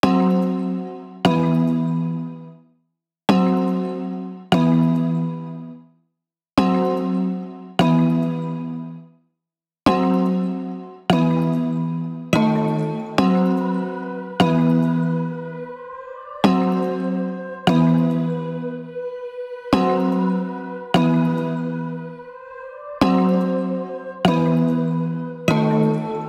SOUTHSIDE_melody_loop_sewers_146_F#m-Em.wav